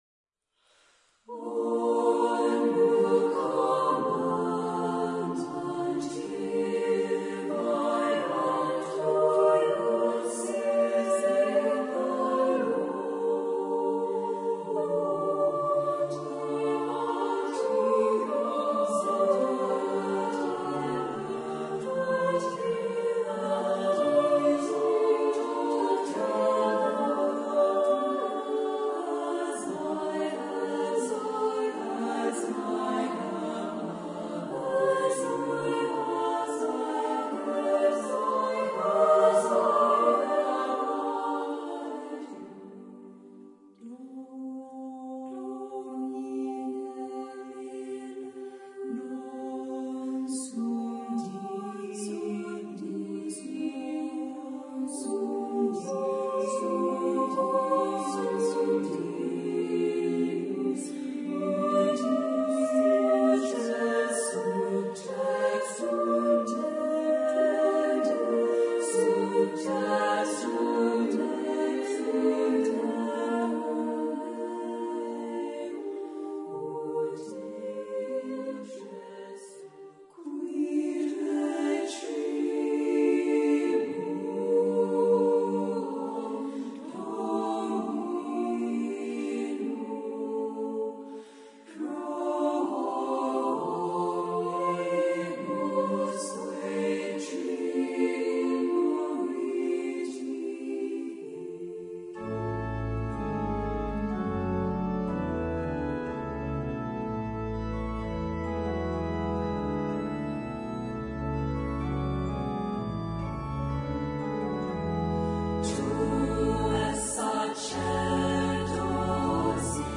And there are some instrumental touches as well.